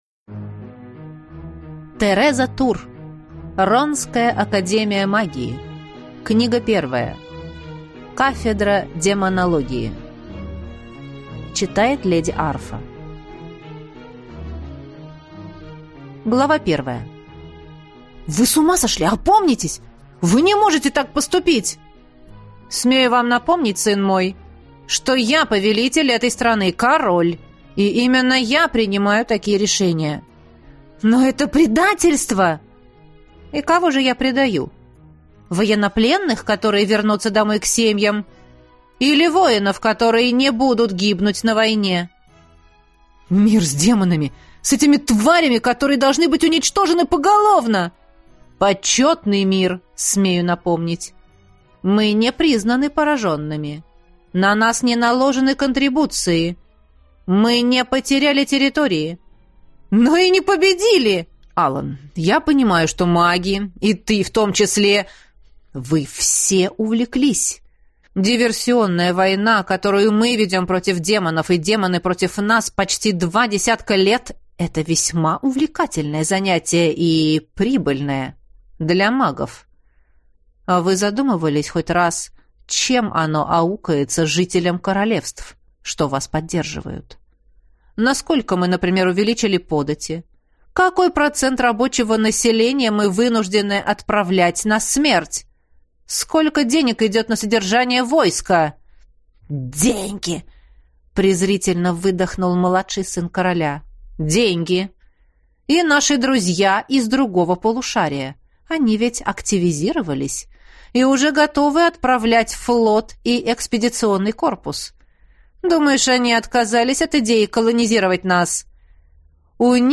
Аудиокнига Кафедра демонологии | Библиотека аудиокниг